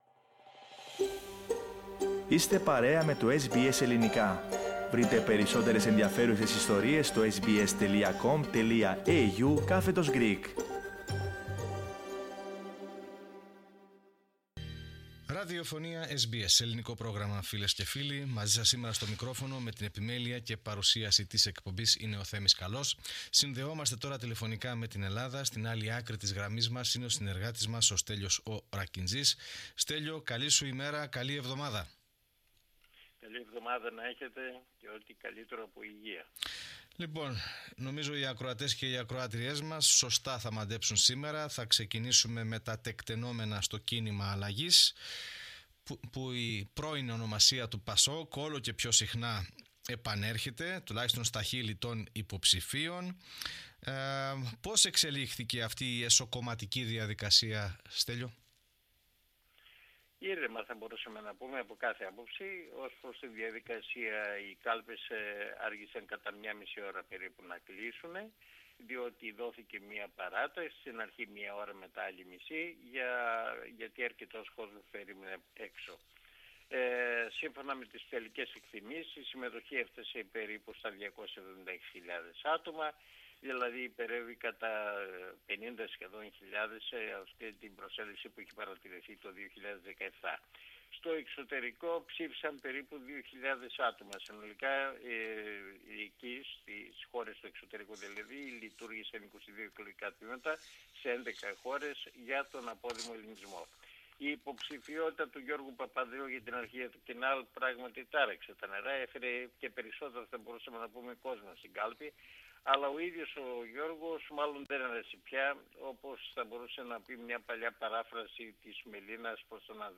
antapokrisi_pod_5.mp3